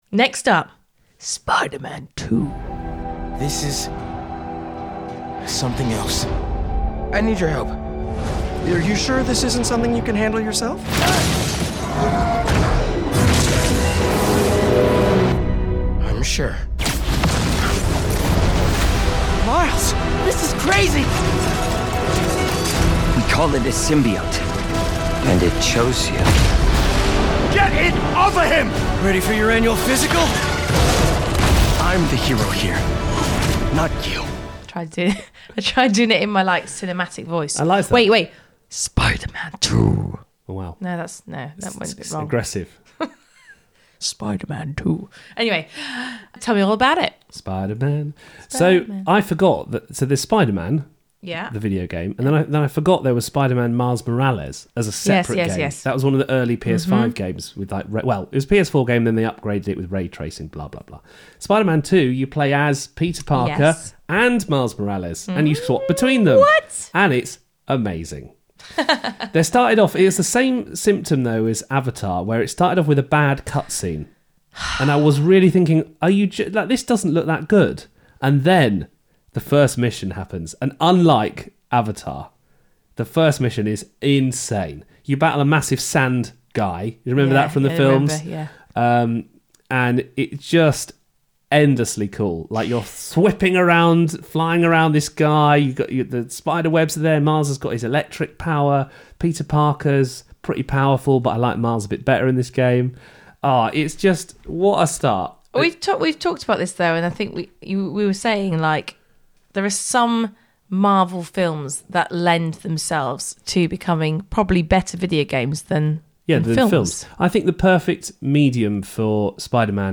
Interview with